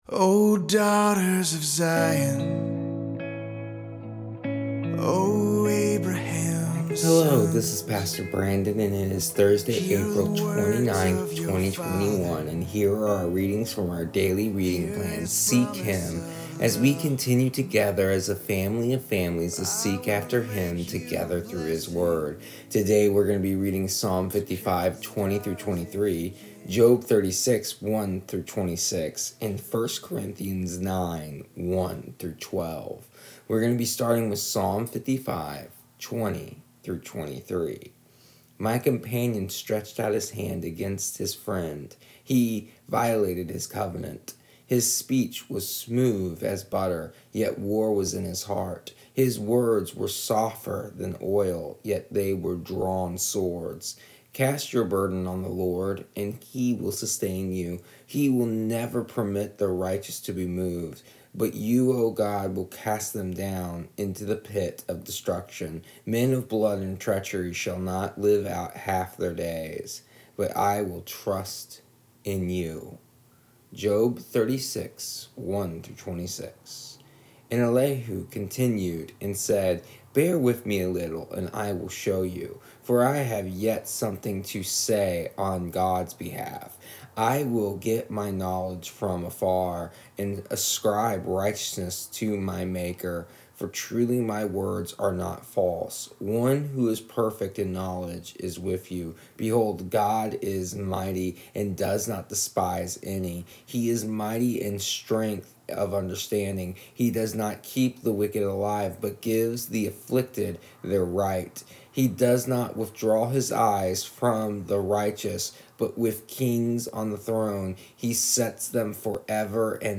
Here is the audio version of our daily readings from our daily reading plan Seek Him for April 29th, 2021.